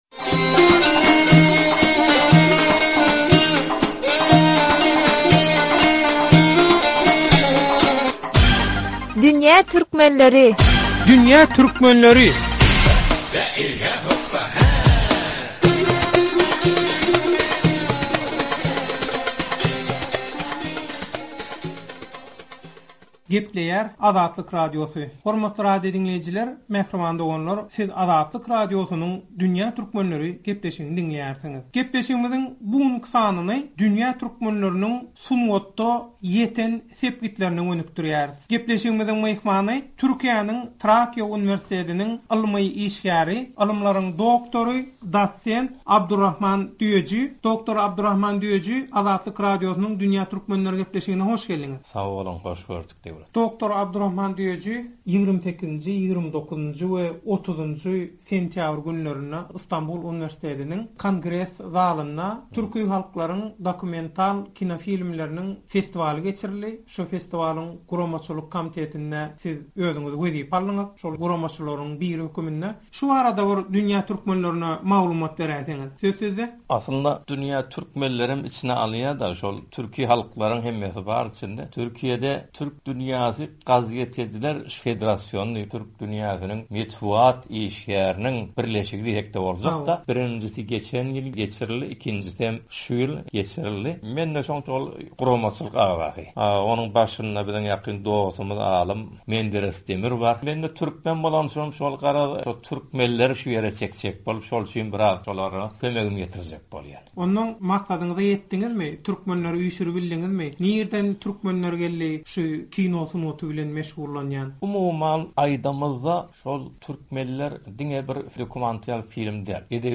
Gepleşigi diňläp, öz pikirleriňizi we tejribeleriňizi aşakdaky foruma teswir görnüşinde ýazyp bilersiňiz.